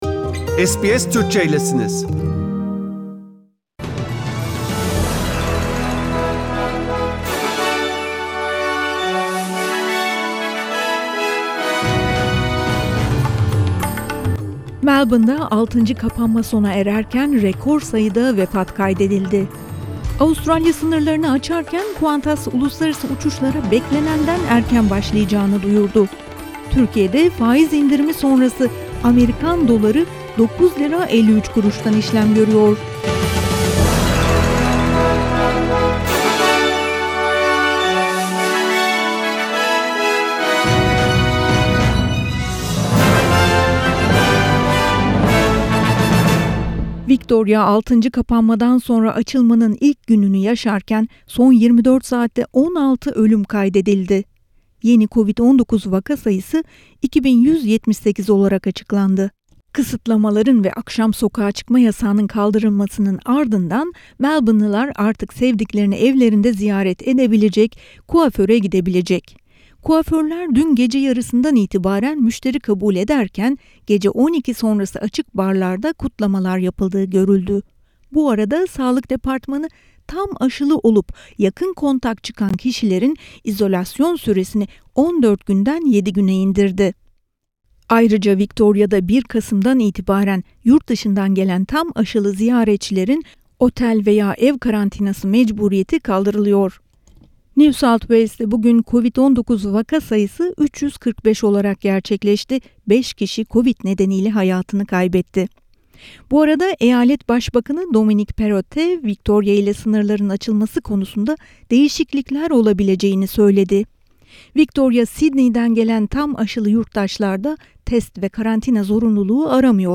SBS Türkçe Haberler 22 Ekim